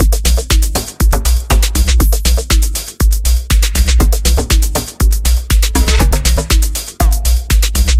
描述：就像它的名字一样......古怪的电子风格的鼓循环组供大家欣赏。1的5
Tag: 120 bpm Electronic Loops Drum Loops 1.35 MB wav Key : Unknown